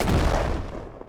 Shot Fire.wav